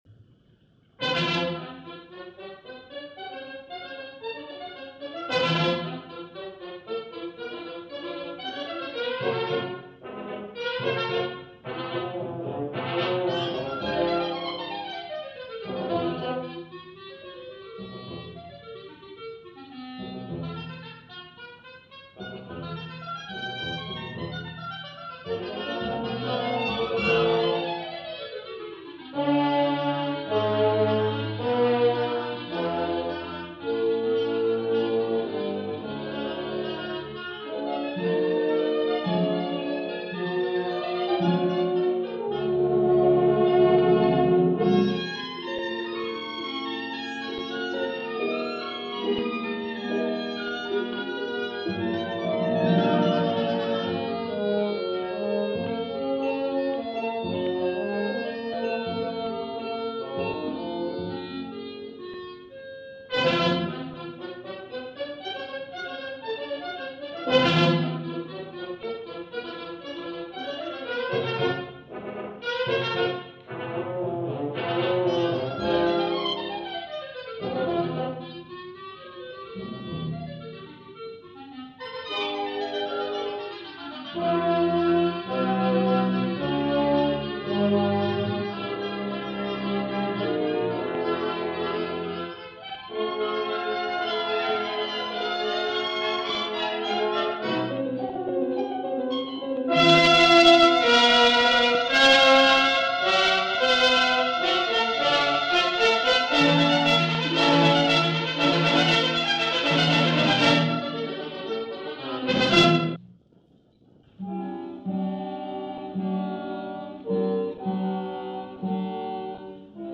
Baritone
Soprano
All part of the same radio session